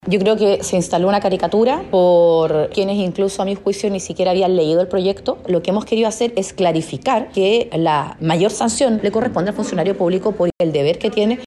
La senadora RN y Presidenta de la Comisión, Paulina Núñez, criticó que se califique la propuesta como una ‘Ley Mordaza’ y al igual que sus colegas, aseguró que es una ‘caricatura’ que demuestra ignorancia sobre la iniciativa.